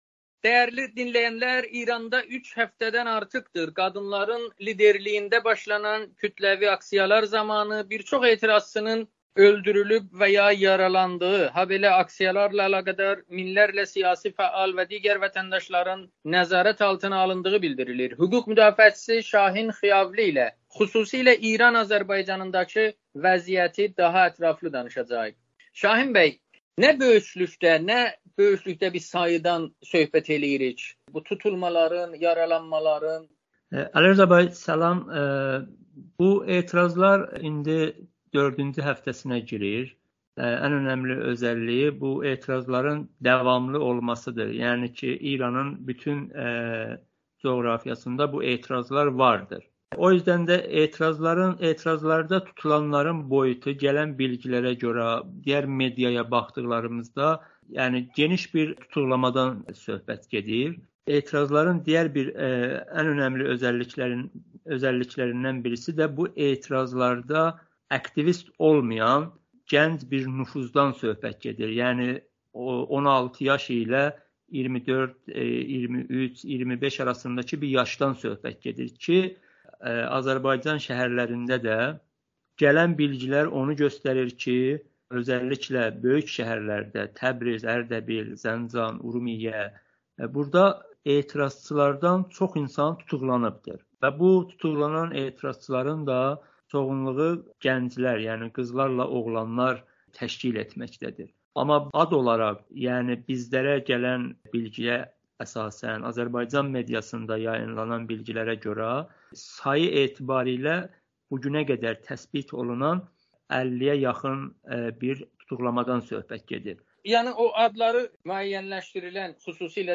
Amerikanın Səsi ilə söhbətdə İranda dördüncü həftəsinə daxil olan kütləvi aksiyalar ilə əlaqədar İran Azərbaycanında saxlanılan fəallar və etirazçılar haqqında məlumat verib.